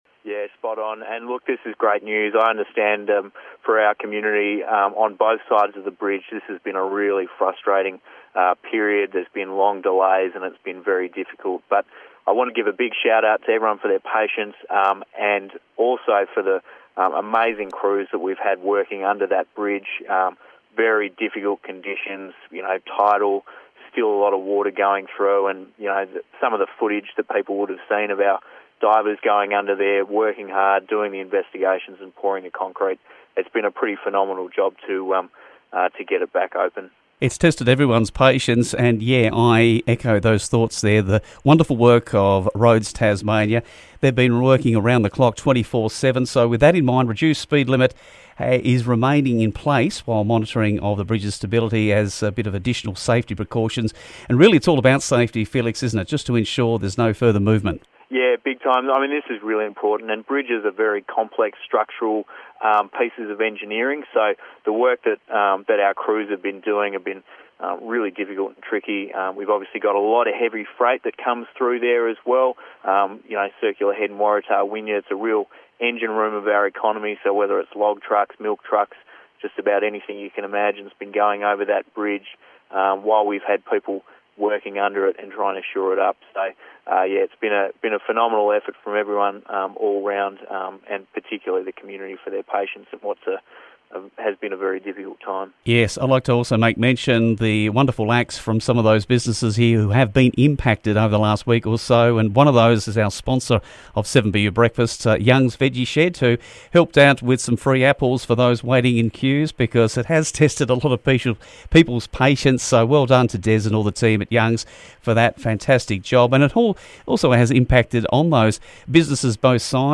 The Cam River Bridge is back with 2 lanes operating and Braddon MP Felix Ellis was apart of 7BU Breakfast.